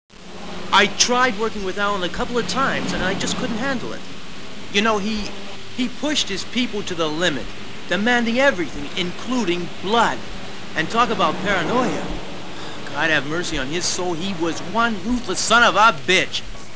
Tom Brabant, an associate of Alan Yates